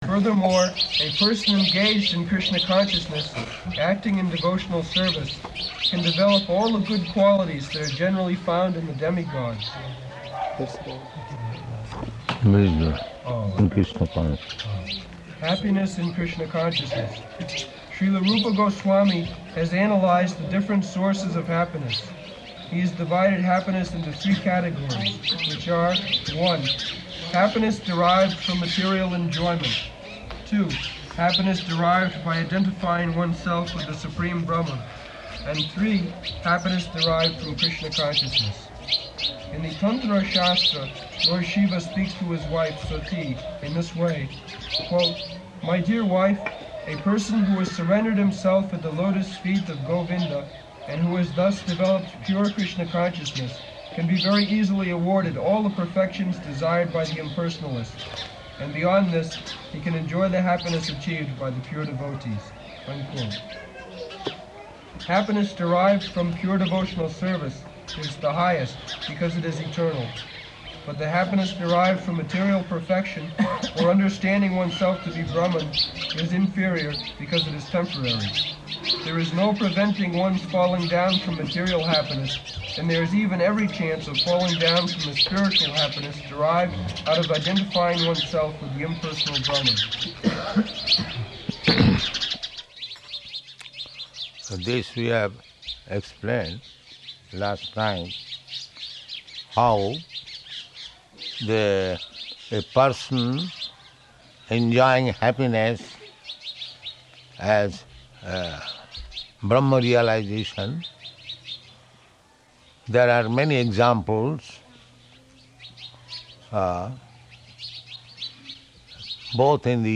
Location: Vṛndāvana